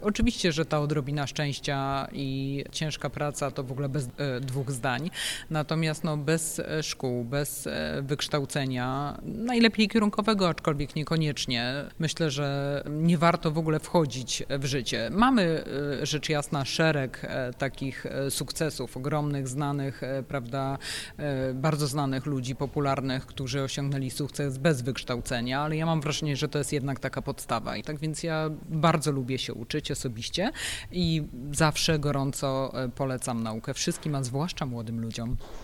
We wtorkowy poranek (20 maja) odbyło się kolejne spotkanie z cyklu „Śniadanie Mistrzów PWT”.